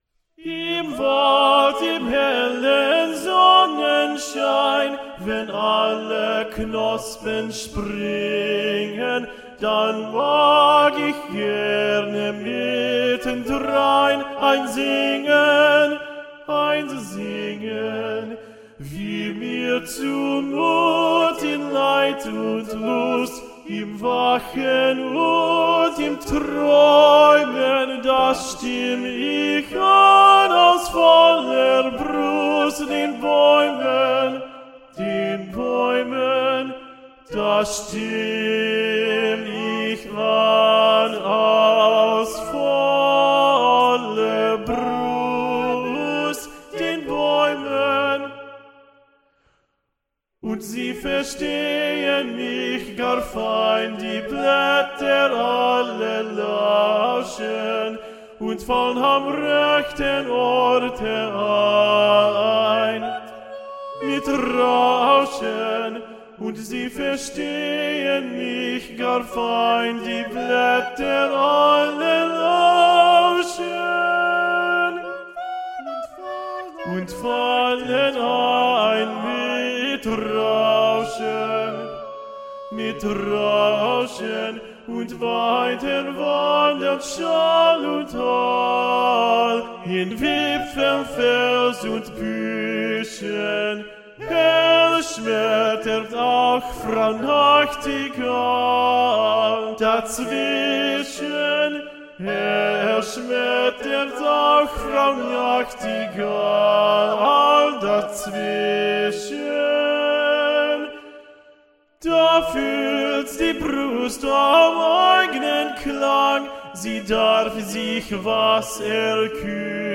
Tenor(mp3)